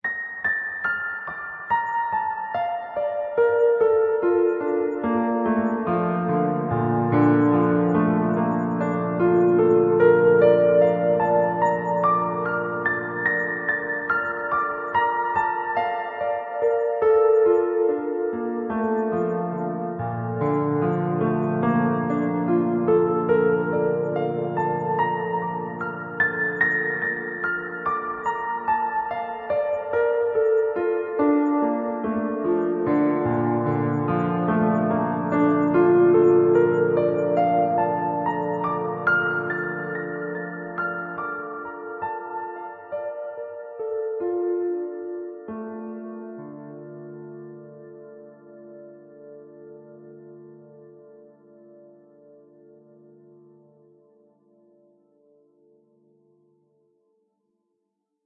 描述：钢琴循环我在我的卡西欧合成器上播放。这是一个几乎没有调整过的录音，在合成器中已经添加了录音磁带合唱。
标签： 向上 钢琴 向下
声道立体声